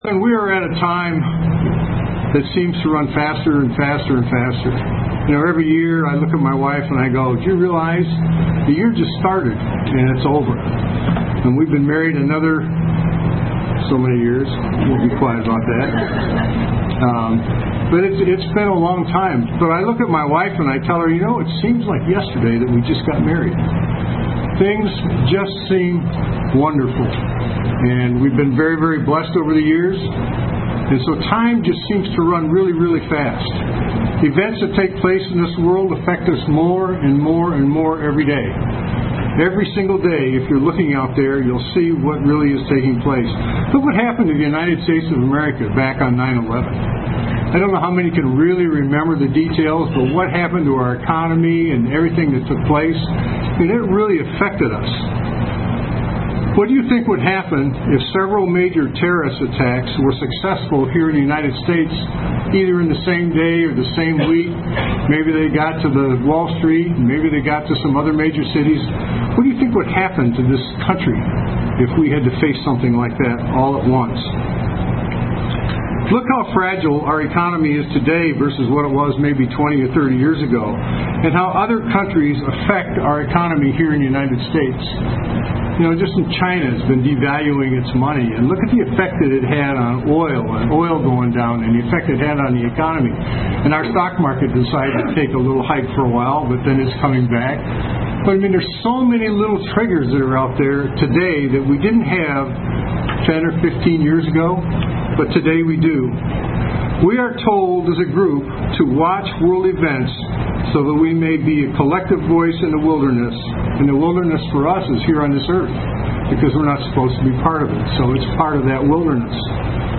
Given in Cincinnati North, OH
UCG Sermon Studying the bible?